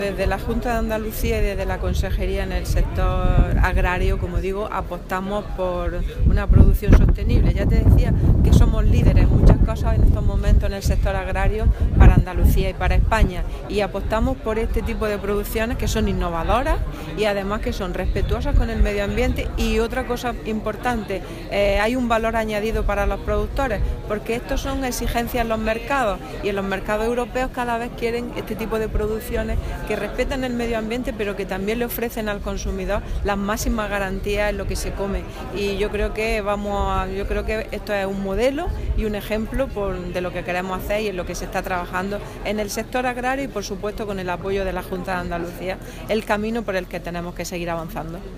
Declaraciones consejera proyecto Zitrus Edeka